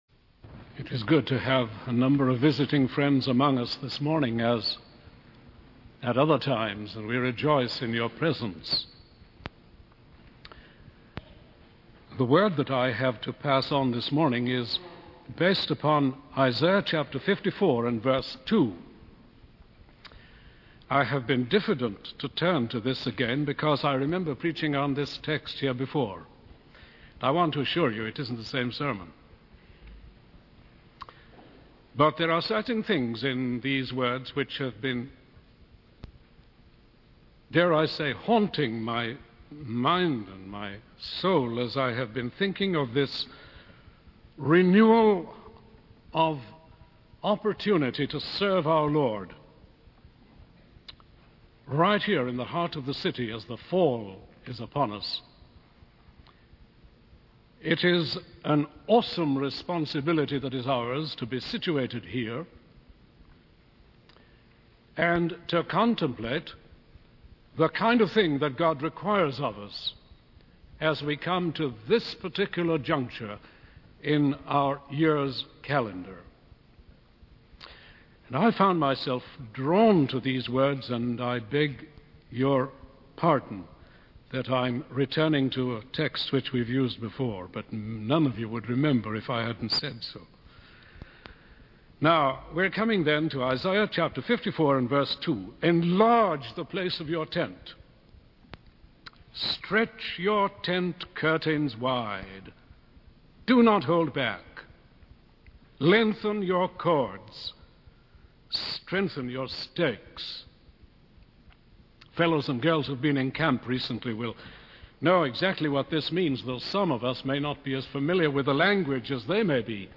In this sermon, the preacher emphasizes the importance of coming near to God and having communion with Him in order to have a clear vision of the world. He contrasts the limited perspective of reporters who only see events from ground level with the broader perspective that comes from being close to God.